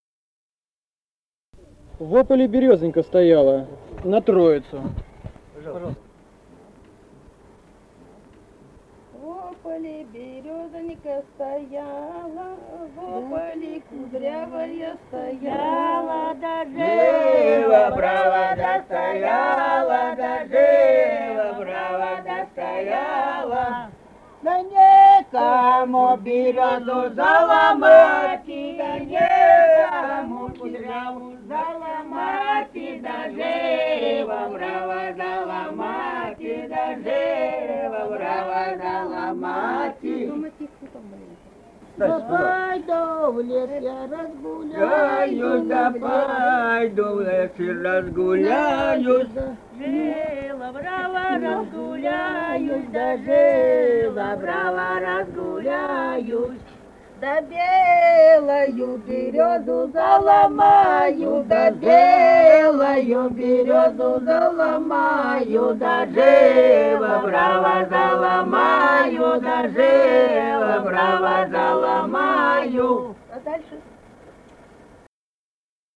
Республика Казахстан, Восточно-Казахстанская обл., Катон-Карагайский р-н, с. Коробиха, июль 1978.